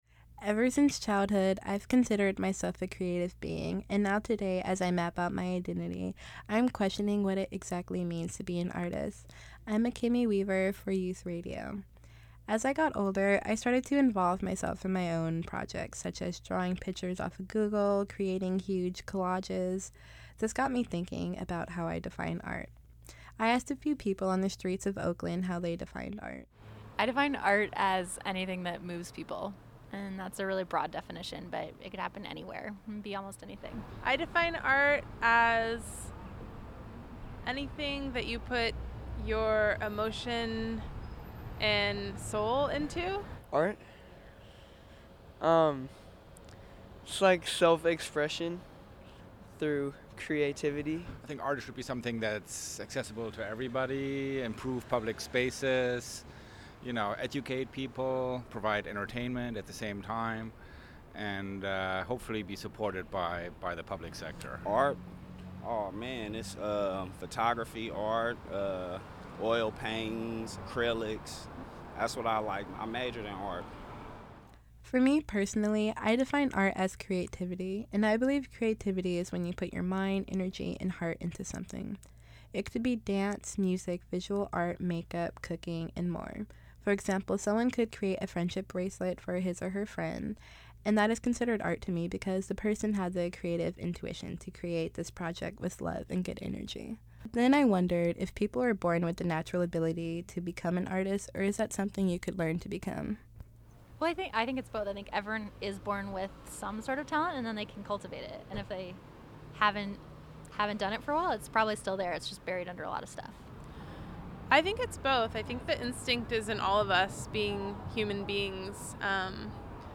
This project also got me thinking about how I defined art. This project includes audio excerpts from different people from the street of Oakland, it also includes a interview I did with a local bay area artist.